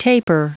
Prononciation du mot taper en anglais (fichier audio)
Prononciation du mot : taper